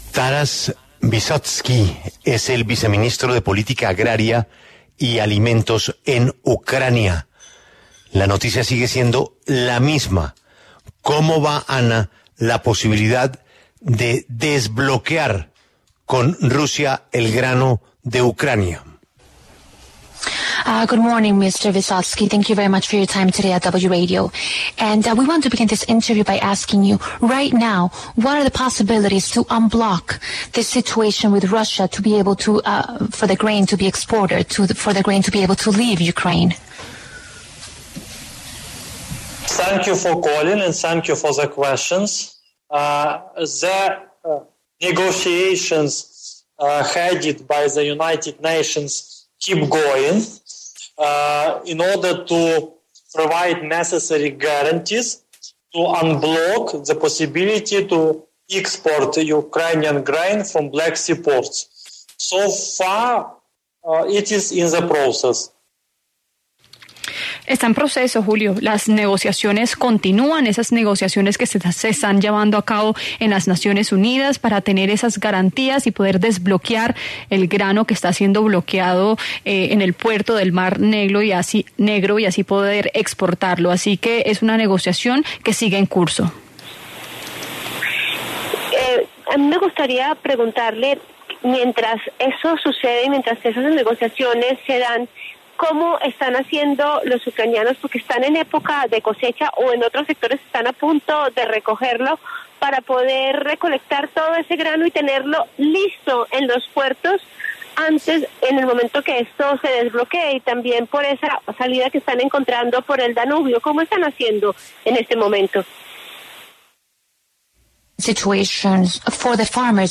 Taras Vysotskyi, viceministro de Política Agraria y Alimentación de Ucrania, habló en La W sobre las garantías que piden a los rusos para limpiar los puertos de minas y exportar los cereales.
En el encabezado escuche la entrevista completa con Taras Vysotskyi, viceministro de Política Agraria y Alimentación de Ucrania.